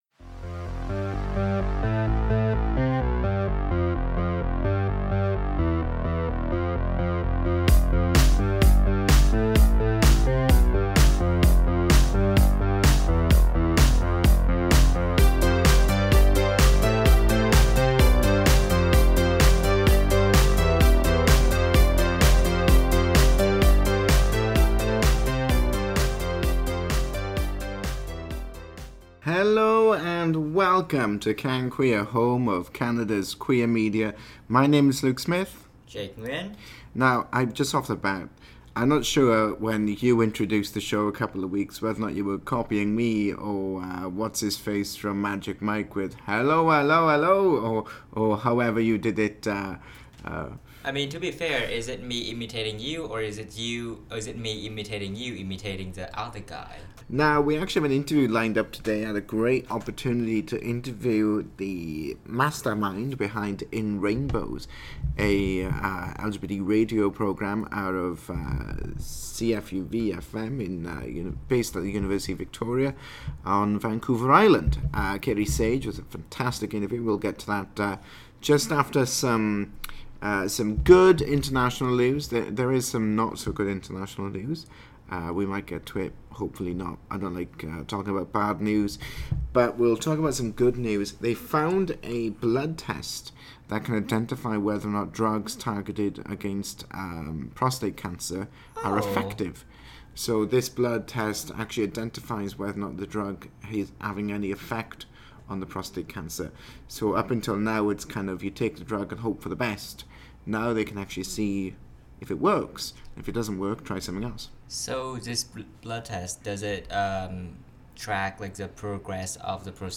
In Rainbows Interview & LGBT News